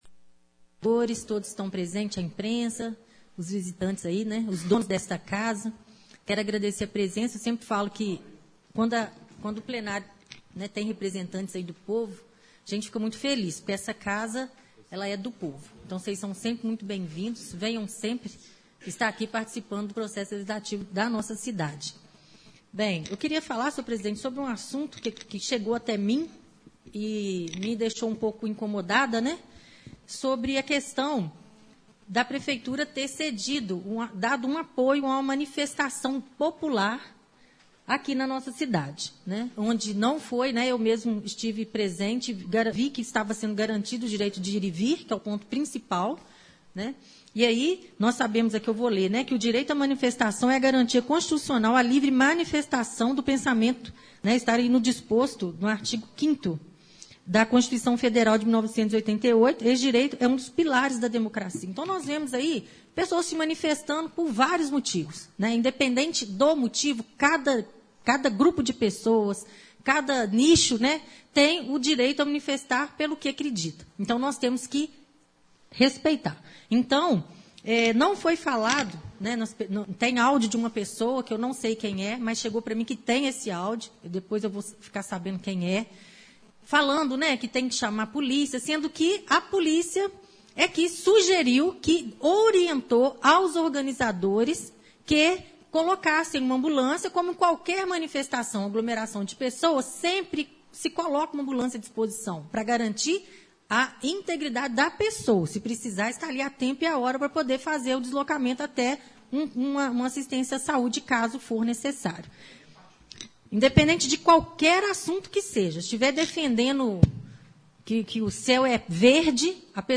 Ouça a palavra da vereadora Aline Mello